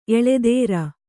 ♪ eḷedēra